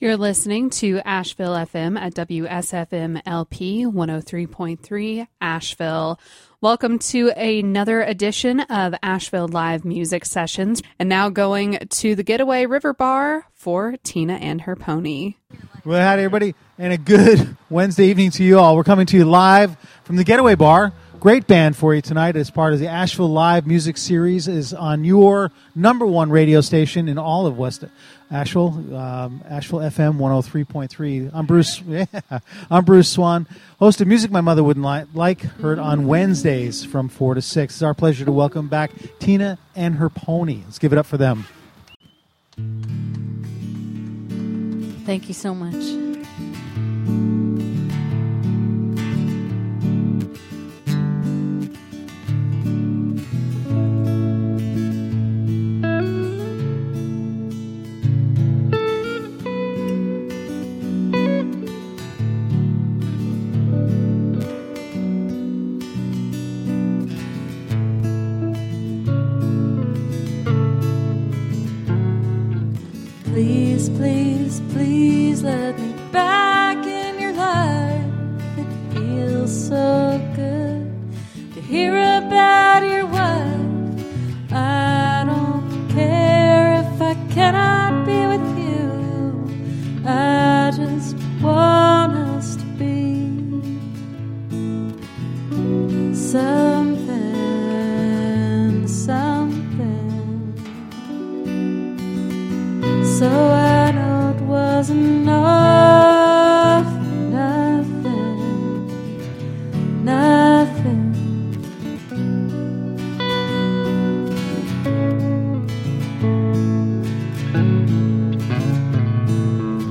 Live from The Getaway River Bar